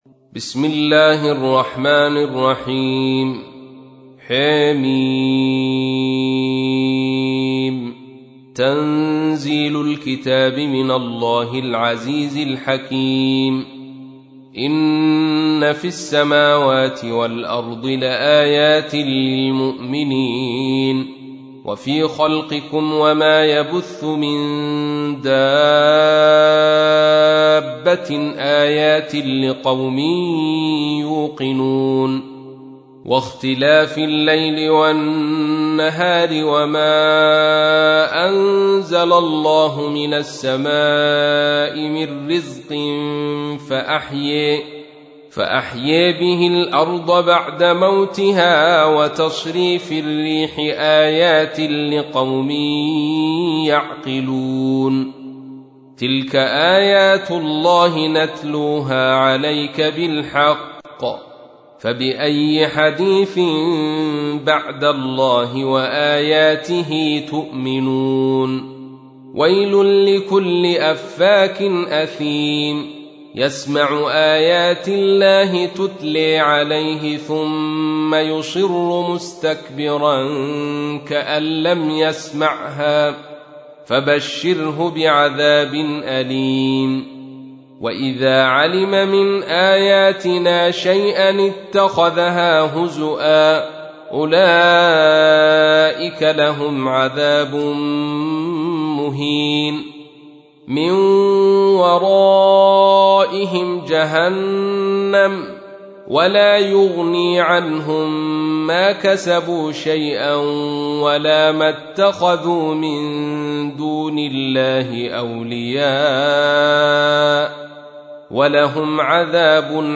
تحميل : 45. سورة الجاثية / القارئ عبد الرشيد صوفي / القرآن الكريم / موقع يا حسين